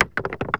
gibstone1.wav